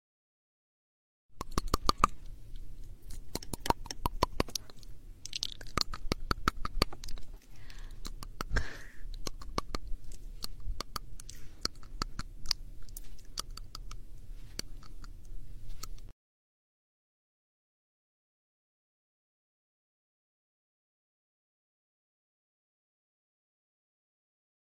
ASMR Tapping the teeth sound effects free download